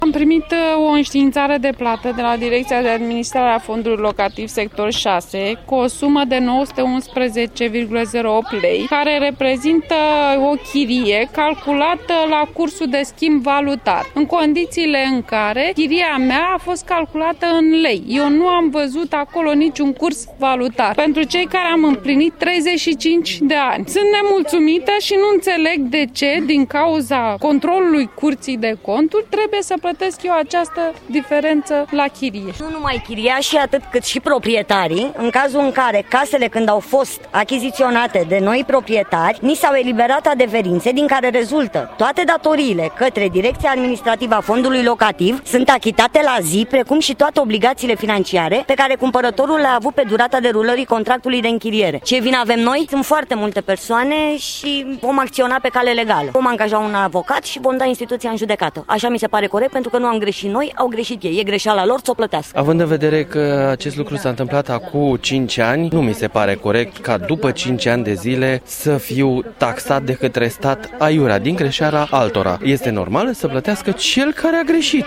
vox-oameni-5-iun.mp3